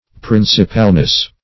Principalness \Prin"ci*pal*ness\, n. The quality of being principal.